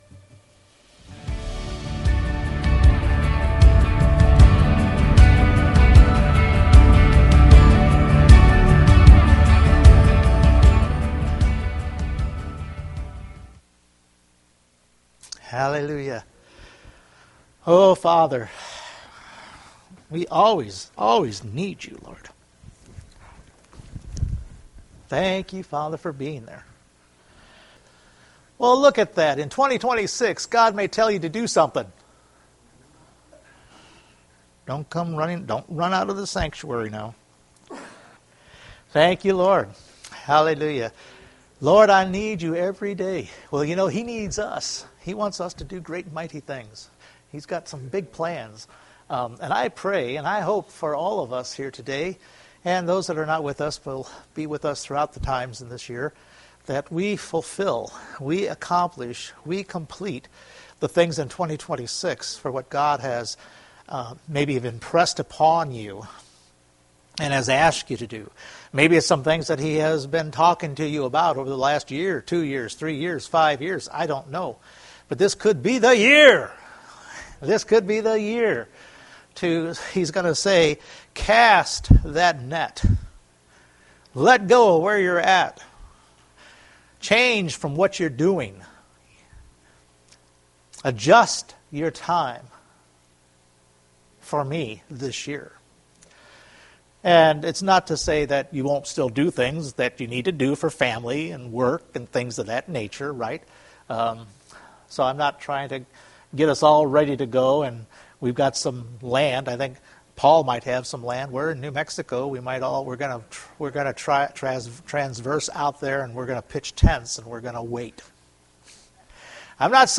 Luke 5:4 Service Type: Sunday Morning Continuing to look at Luke 5:4.